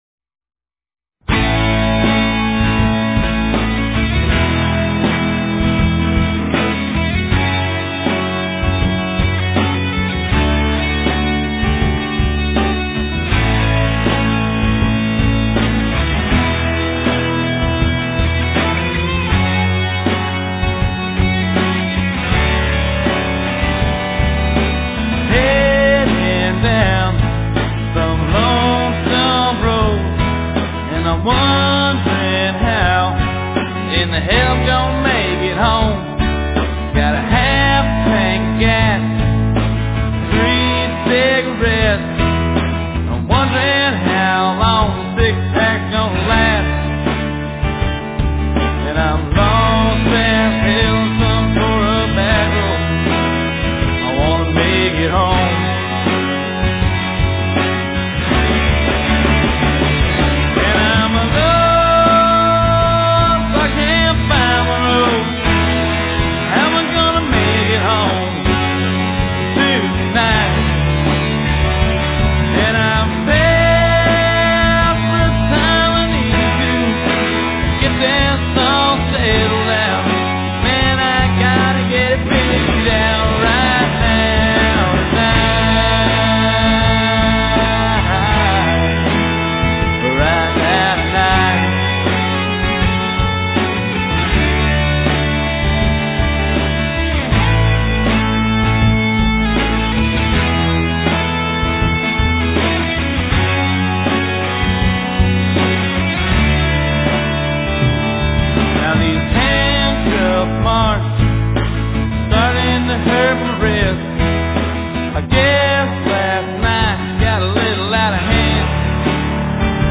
Lead Vocal/Rhythm Guitar
Lead Guitar
Bass Guitar
Drums